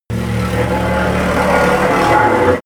Gemafreie Sounds: Metall